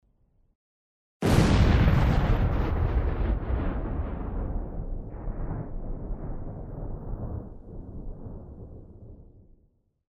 Звуки динамита